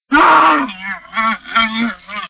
c_camel_dead.wav